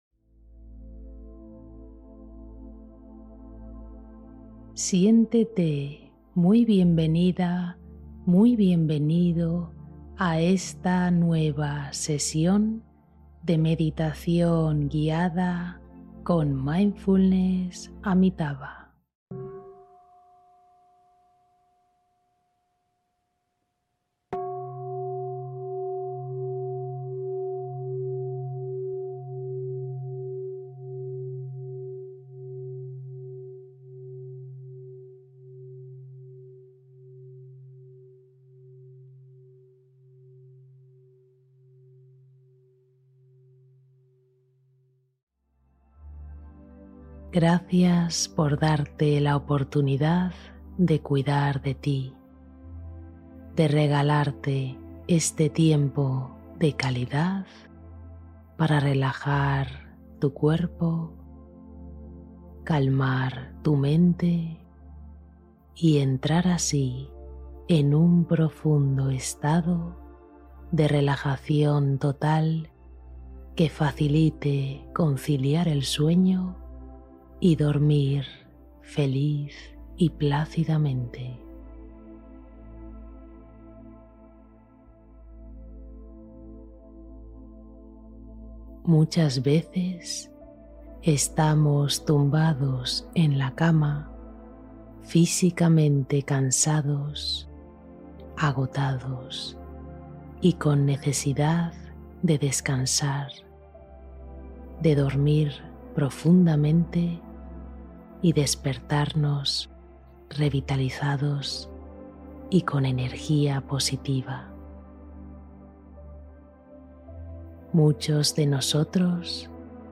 Descanso reparador: una meditación para cerrar el día con suavidad